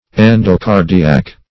Meaning of endocardiac. endocardiac synonyms, pronunciation, spelling and more from Free Dictionary.
Search Result for " endocardiac" : The Collaborative International Dictionary of English v.0.48: Endocardiac \En`do*car"di*ac\, Endocardial \En`do*car"di*al\, a. 1.